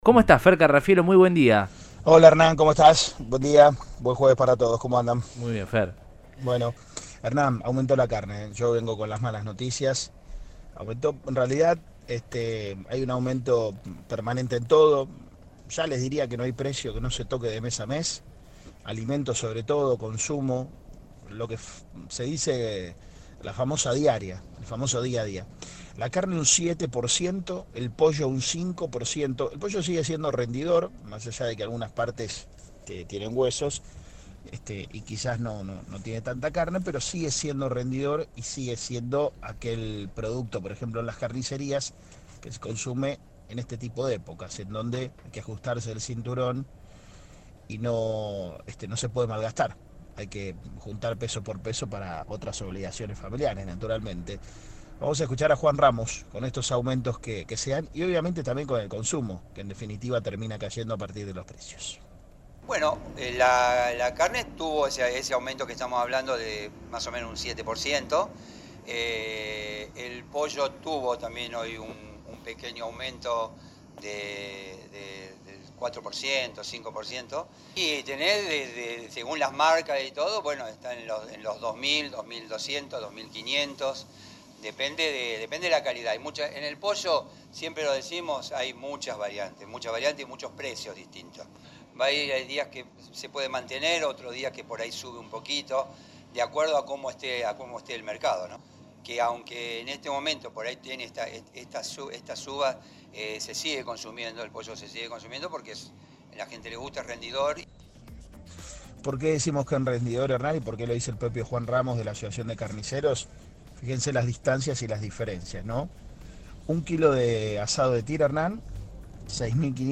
dijo al móvil de Cadena 3 Rosario que en los productos avícolas se puede observar “mucha variedad y precios” en base a las distintas marcas.